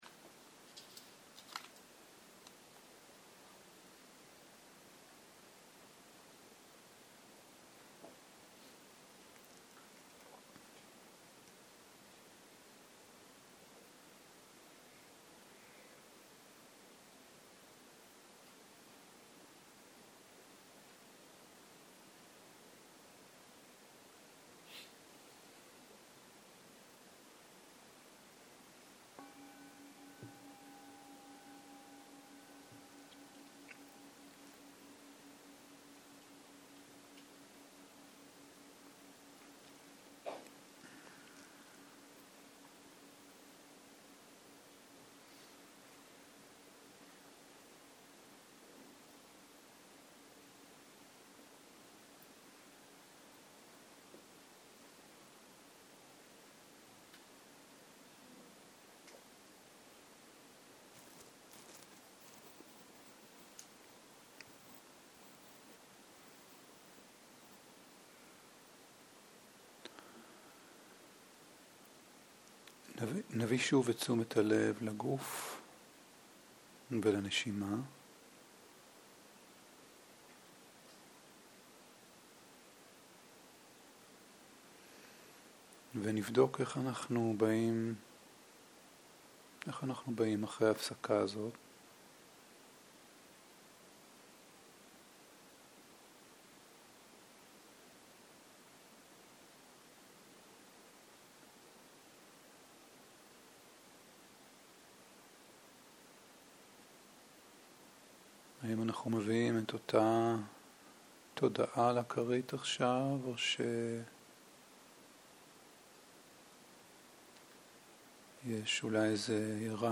צהריים - מדיטציה מונחית - תשומת לב לתחושות - הקלטה 6